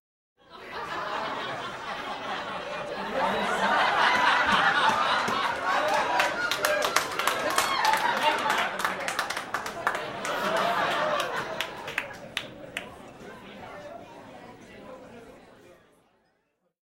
Звуки аплодисментов
Звонкий смех и громкие аплодисменты